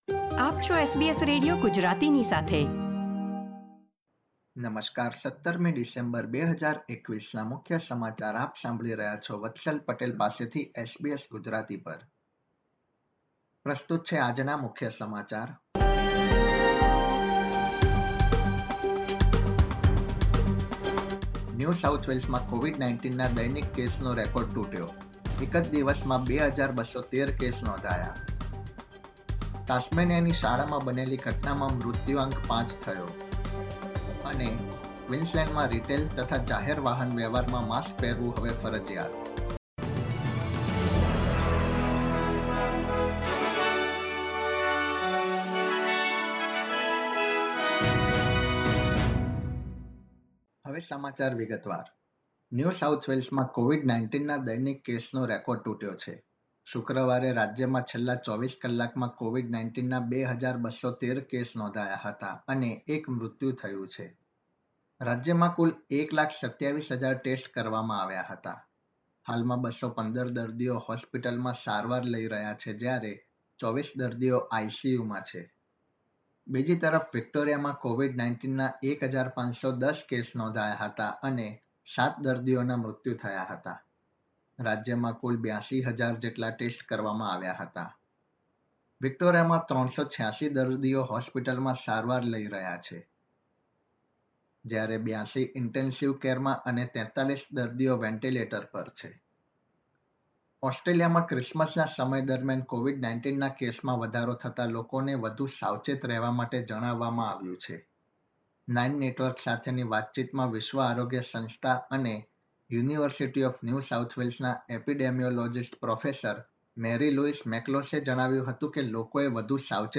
SBS Gujarati News Bulletin 17 December 2021
gujarati_1712_newsbulletin.mp3